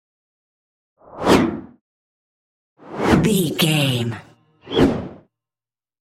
Whoosh fast bright x3
Sound Effects
Atonal
Fast
bright
futuristic
whoosh